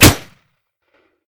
gp30_grenshoot.ogg